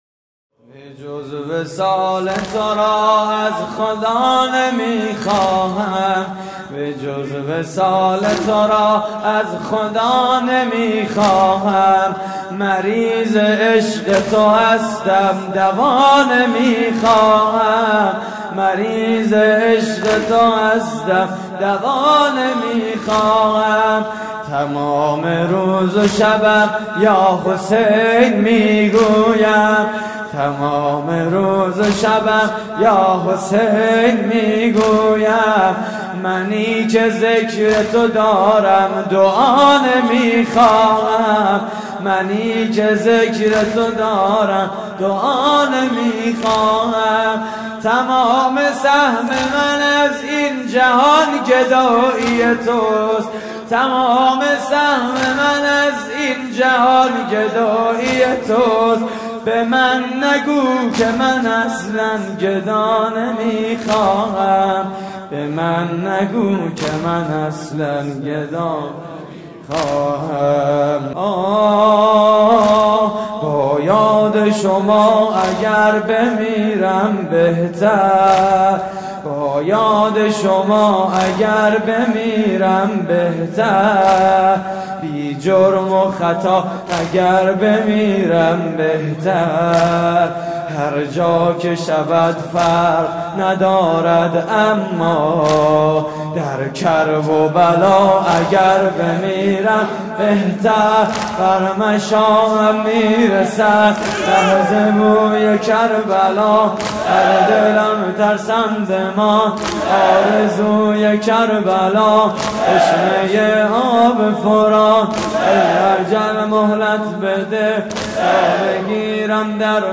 مراسم عزاداری شب دوازدهم (محرم 1433)